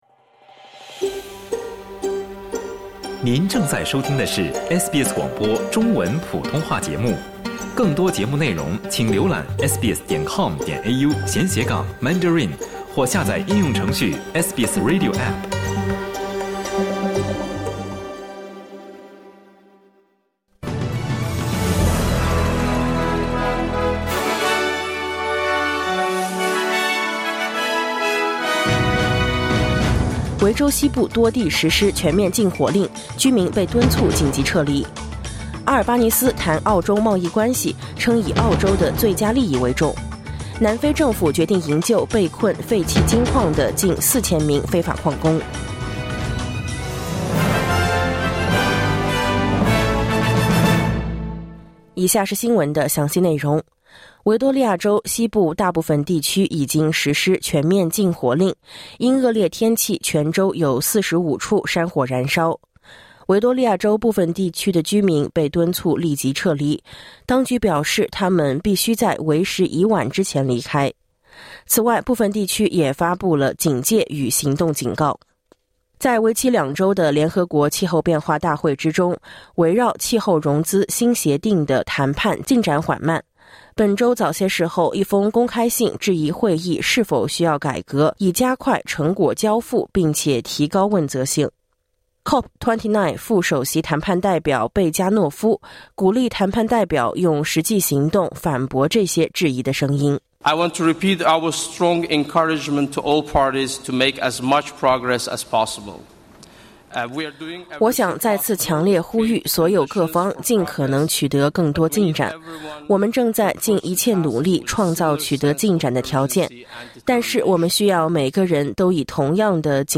SBS早新闻（2024年11月17日）